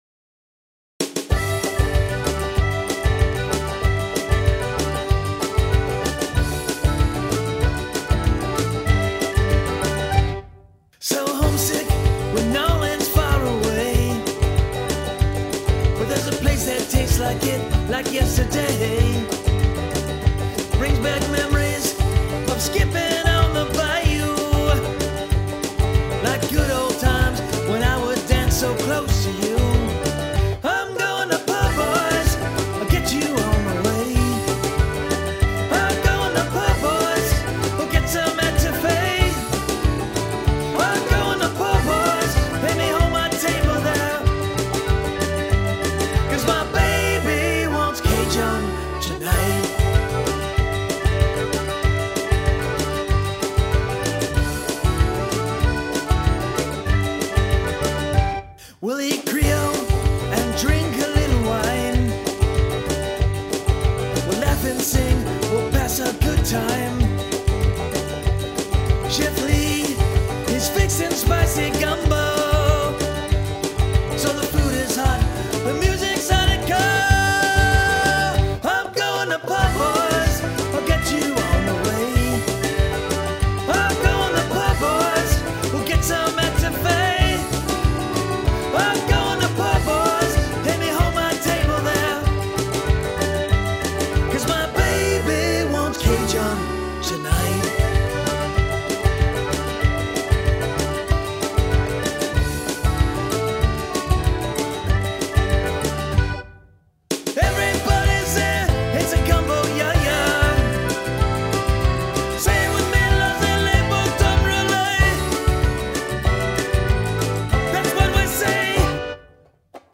whipped together the song in the recording studio at his home.